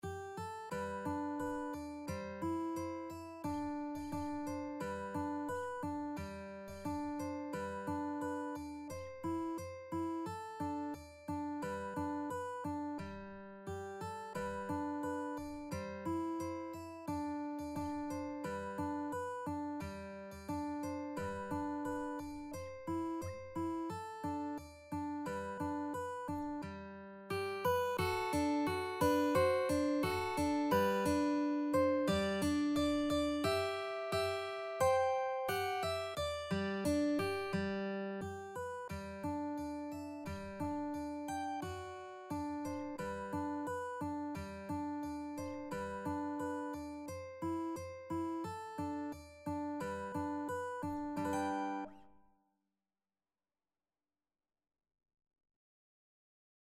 Pop Trad. Muss i denn (aka Wooden Heart) Mandolin version
G major (Sounding Pitch) (View more G major Music for Mandolin )
Andante =c.88
4/4 (View more 4/4 Music)
Mandolin  (View more Easy Mandolin Music)
Traditional (View more Traditional Mandolin Music)